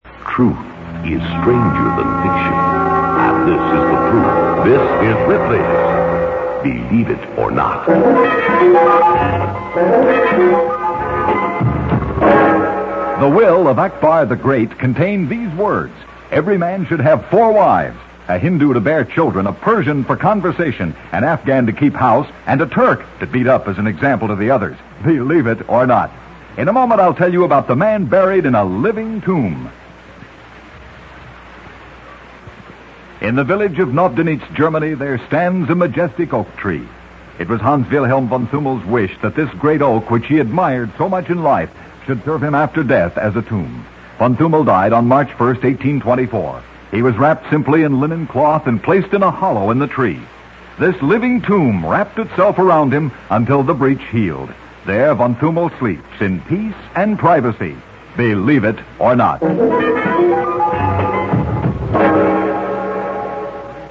Home > Old Time Radio > Ripley's Believe It or Not (One Minute) > Ripley's Believe It or Not 1 Minute 273 - Living Tomb
Audio Info Channels: 1 | Mode: mono | Bitrate: 32kHz | Sample rate: 22.05kHz